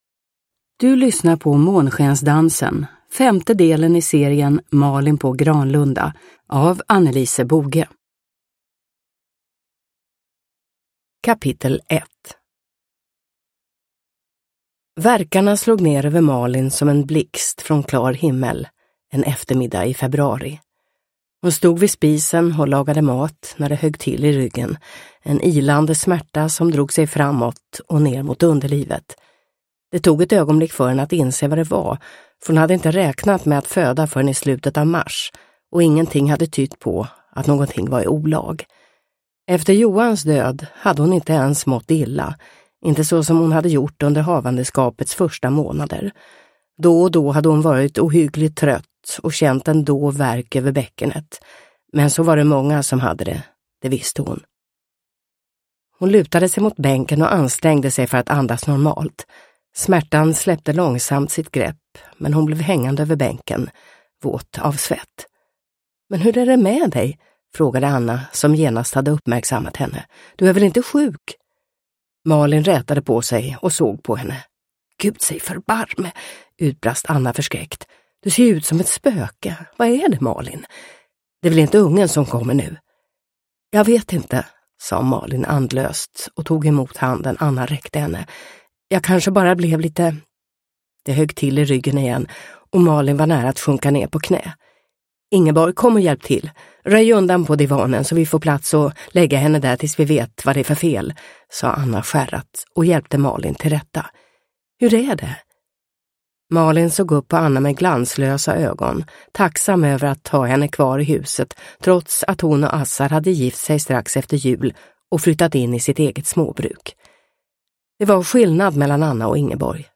Månskensdansen – Ljudbok – Laddas ner